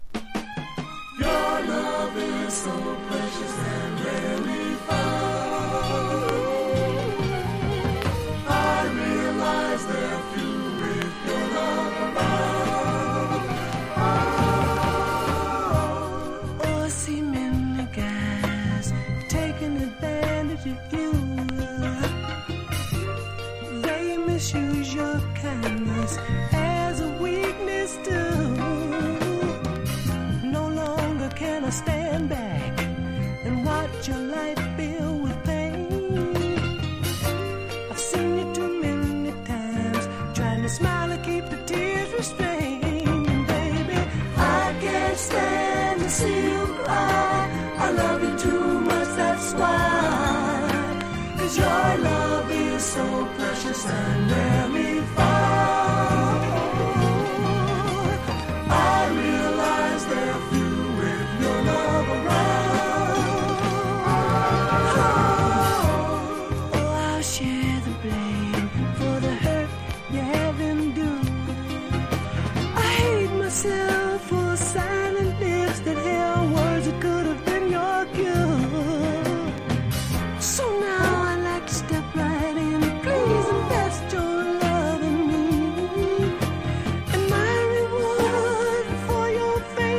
かな～り甘めで心地良いです。
# 甘茶ソウル